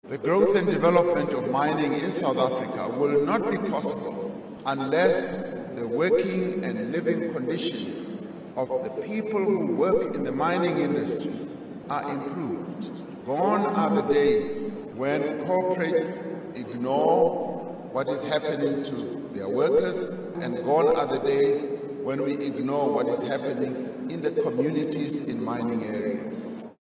Addressing delegates at the Mining Indaba at the CTICC, he said it is important that mining companies not only invest in their infrastructure and operations but also in the development and well-being of their employees.